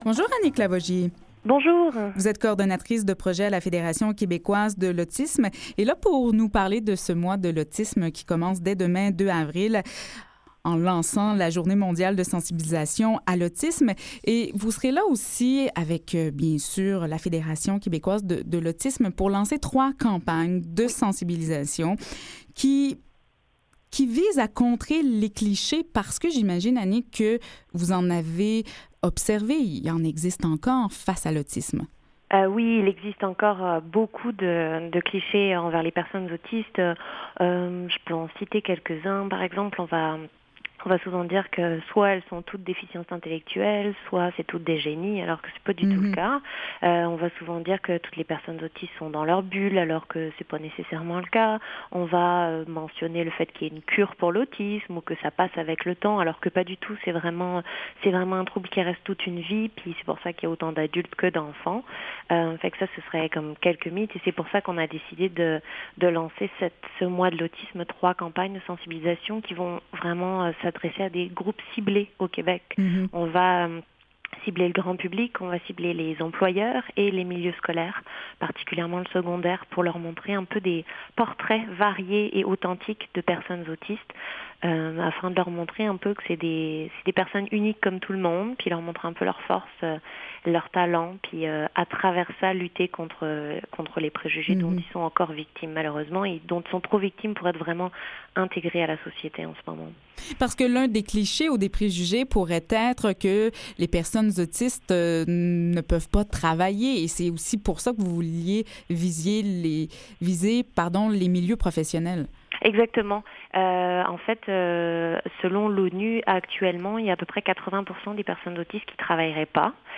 Animation